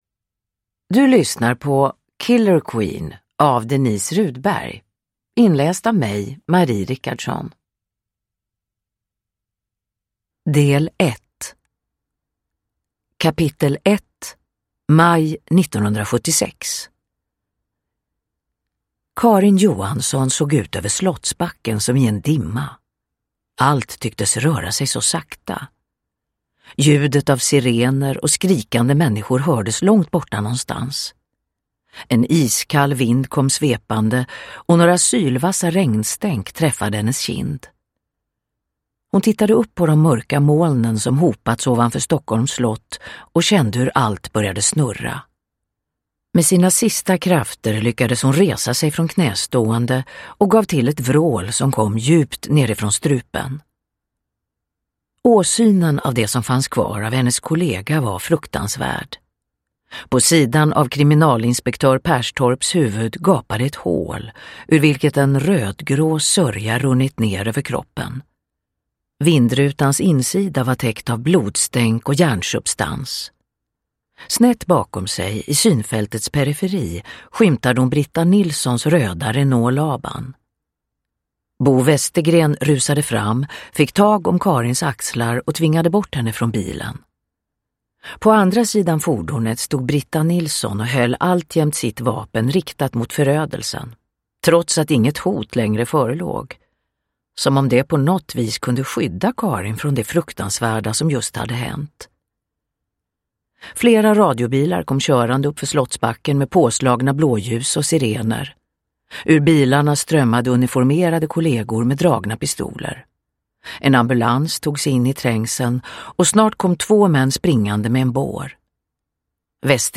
Killer Queen – Ljudbok
Uppläsare: Marie Richardson